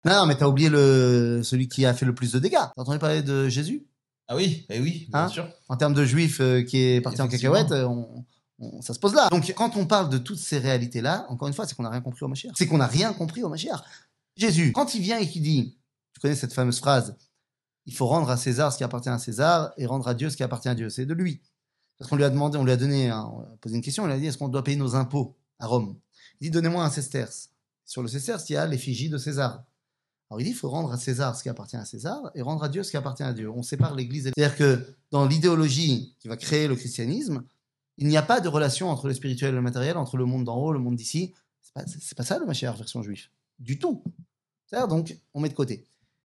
קטגוריה Le messie juif 00:00:47 Le messie juif שיעור מ 28 יוני 2023 00MIN הורדה בקובץ אודיו MP3